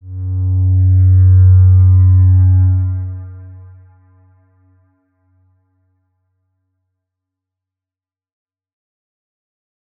X_Windwistle-F#1-pp.wav